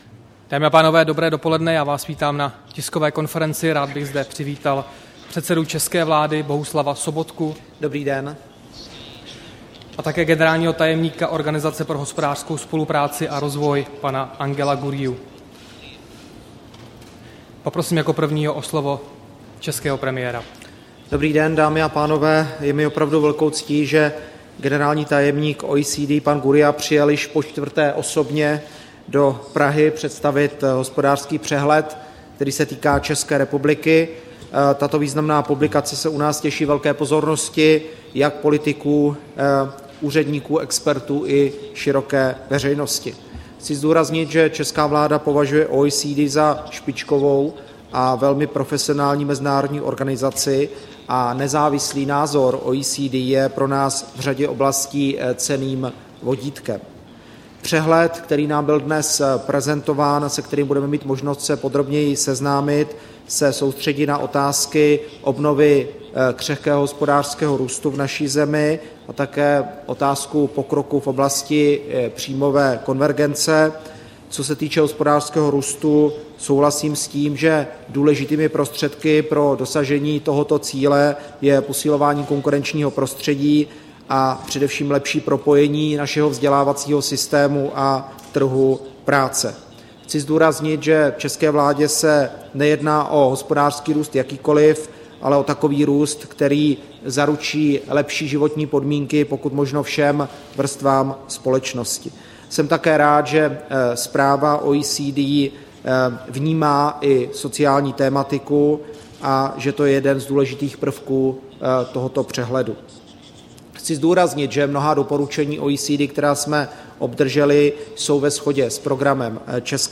Tisková konference po jednání premiéra Bohuslava Sobotky s generálním tajemníkem OECD Angelem Gurríou, 18. března 2014